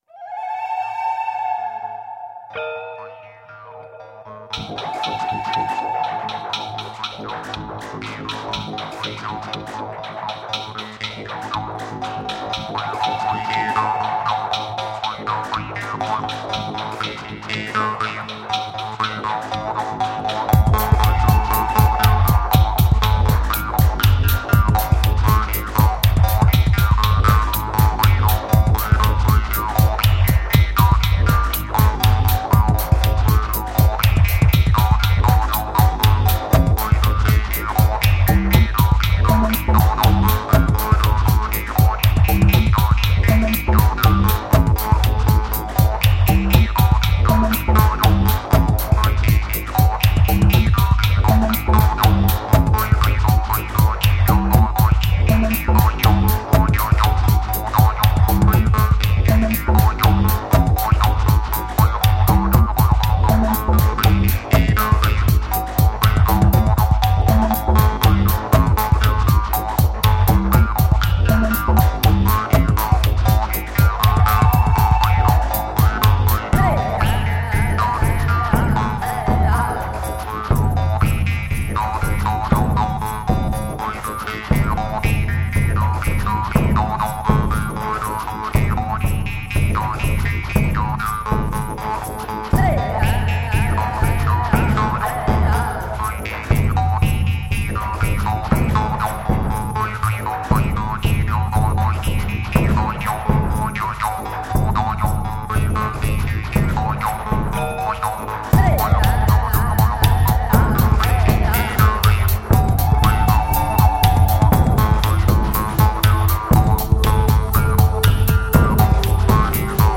New age/world music for mankind from russia.
New Age meets World Music.
Tagged as: New Age, Inspirational, Chillout